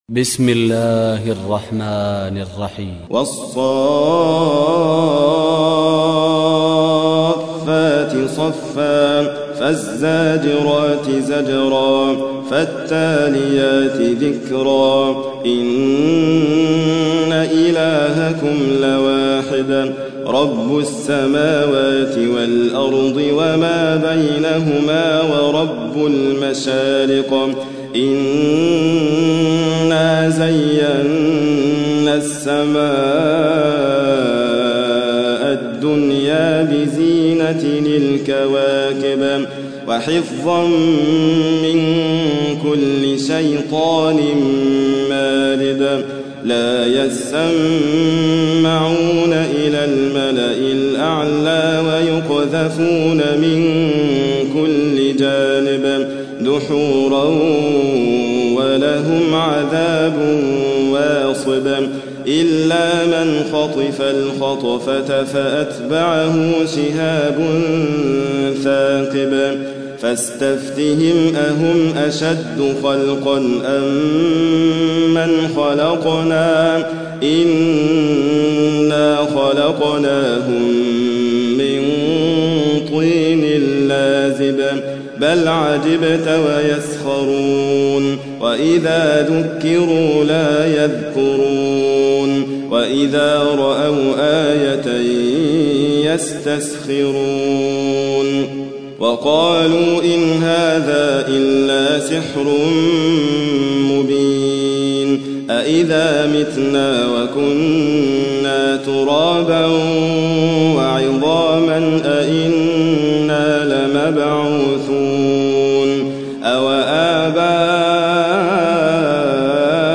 37. سورة الصافات / القارئ